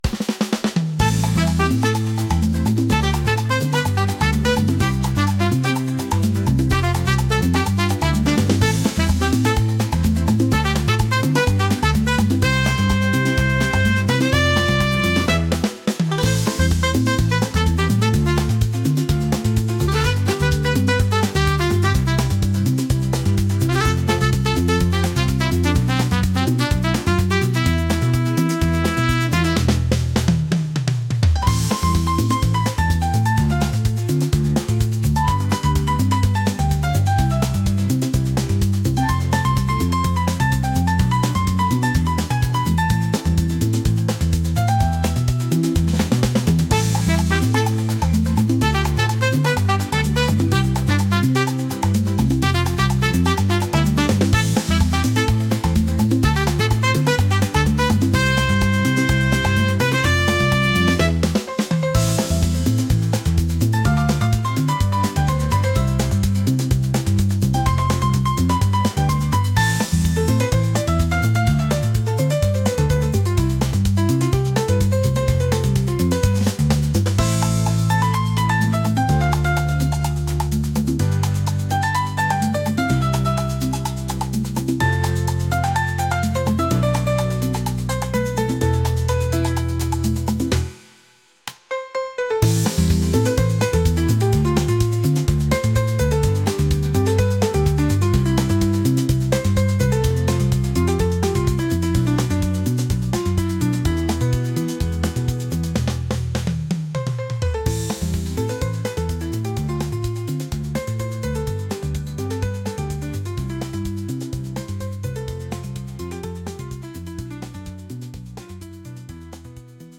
jazz | upbeat | latin